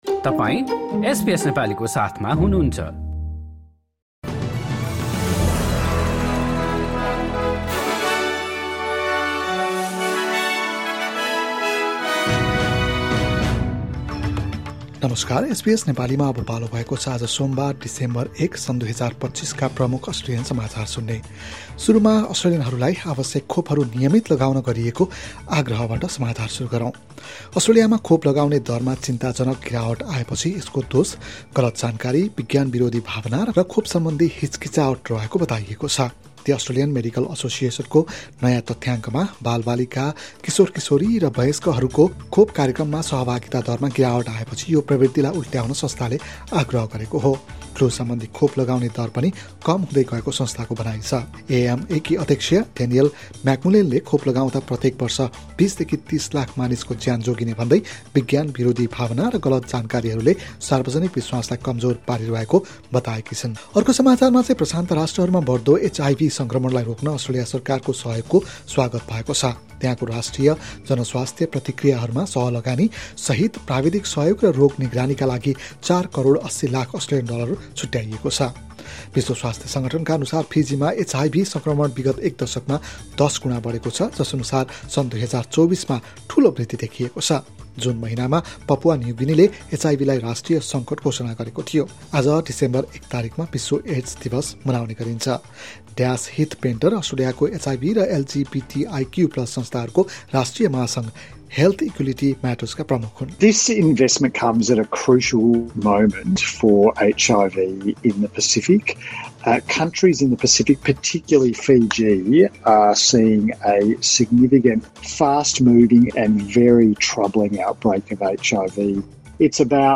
SBS Nepali Australian News Headlines: Monday, 1 December 2025